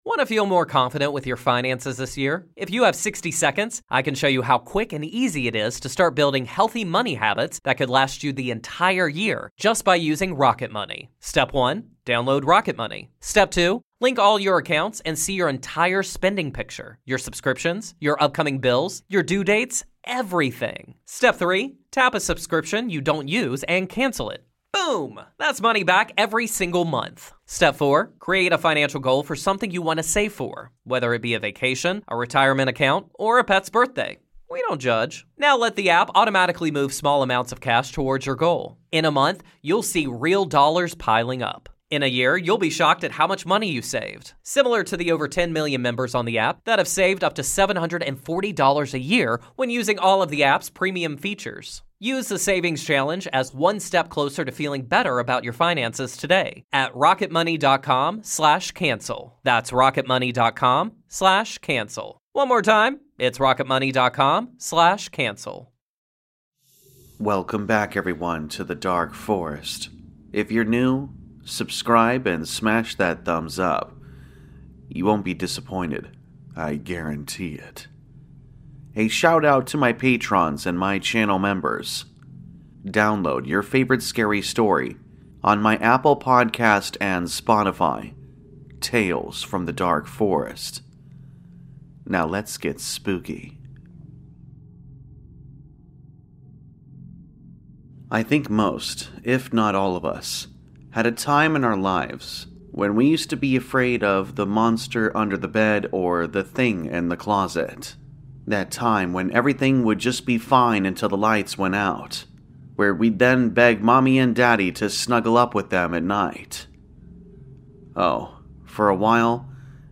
All Stories are read with full permission from the authors: